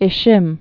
(ĭ-shĭm)